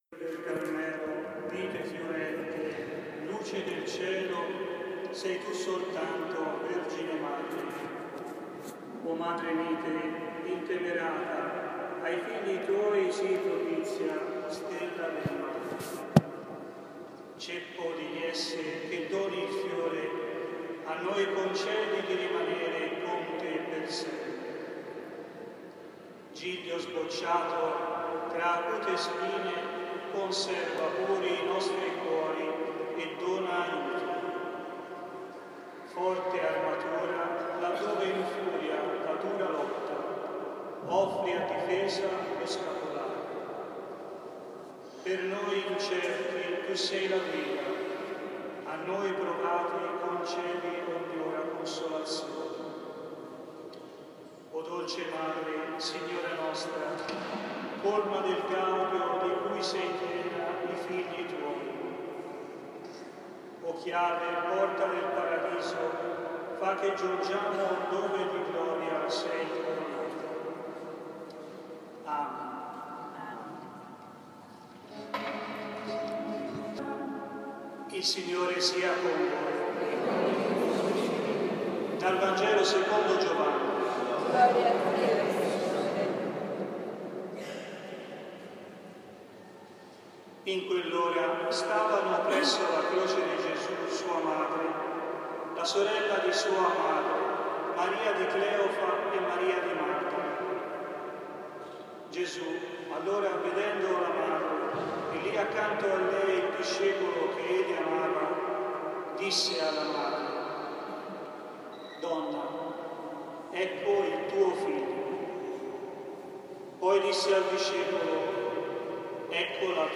Omelia
nella Basilica di Santa Maria del Carmine (FI) – Vangelo di Giovanni cap. 19, 25-27.